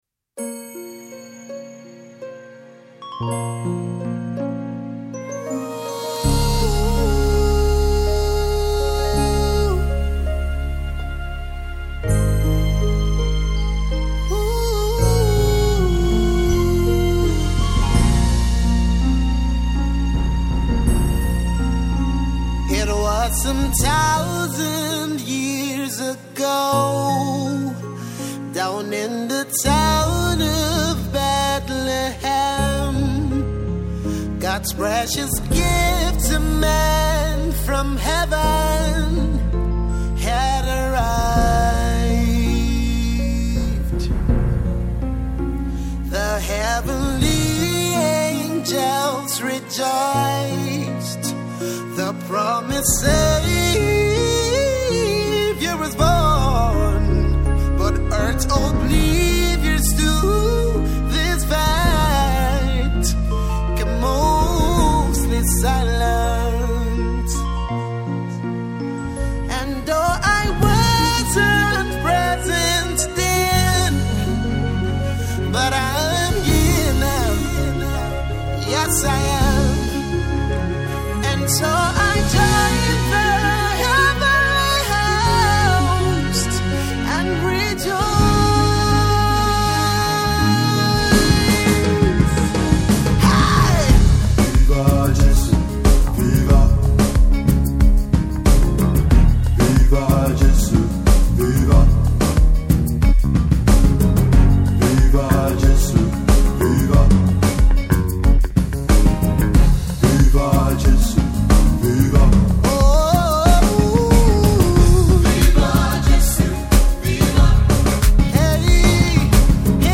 Christmas Single
Nigerian born singer